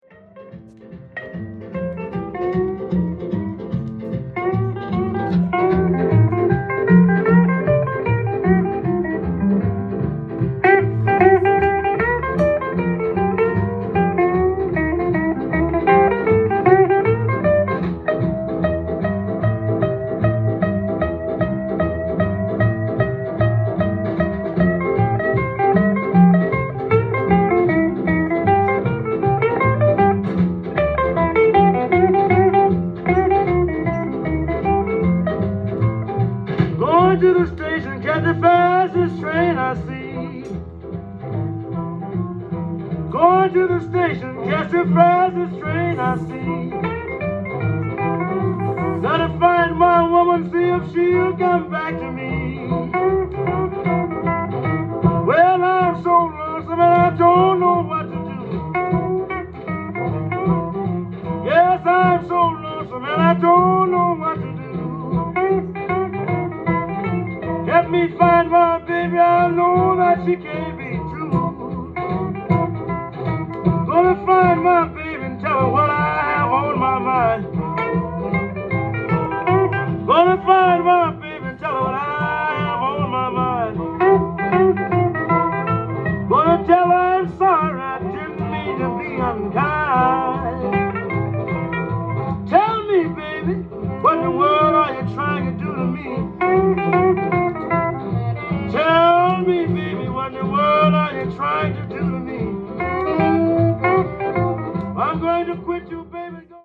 ジャンル：BLUES
店頭で録音した音源の為、多少の外部音や音質の悪さはございますが、サンプルとしてご視聴ください。